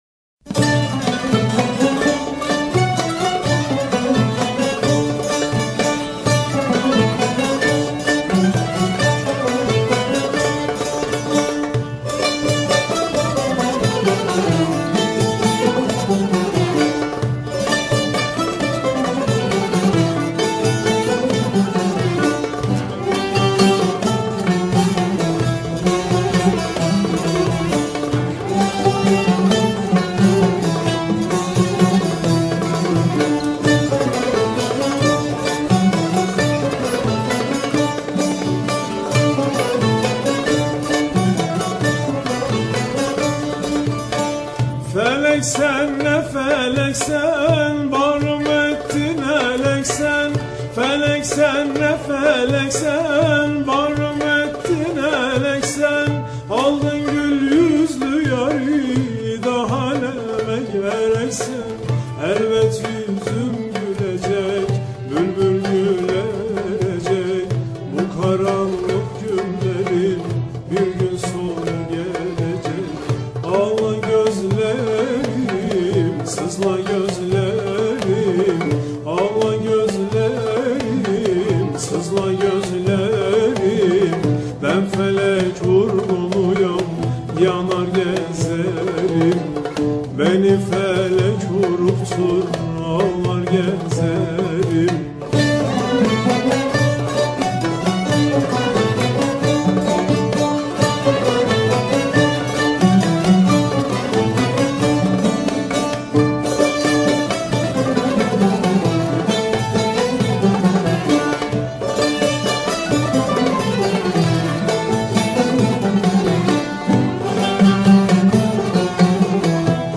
Etiketler: türkiye, türkü